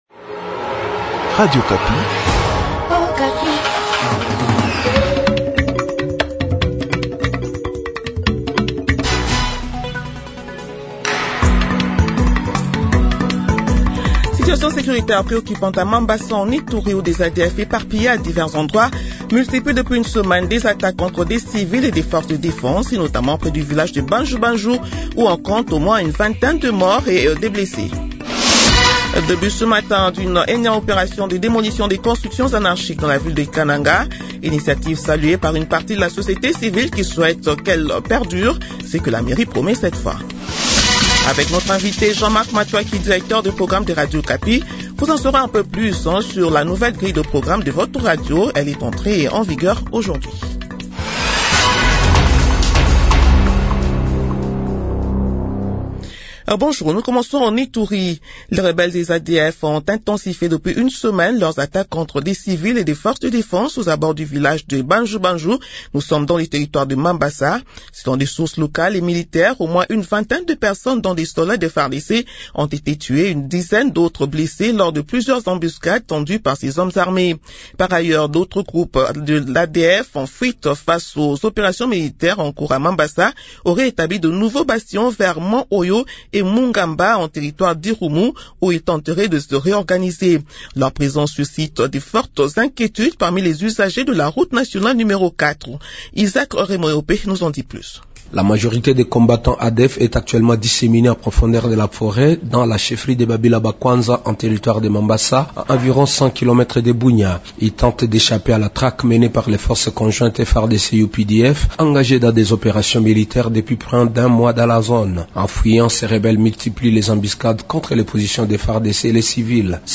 Journal Midi du Lundi 12 Janvier 2026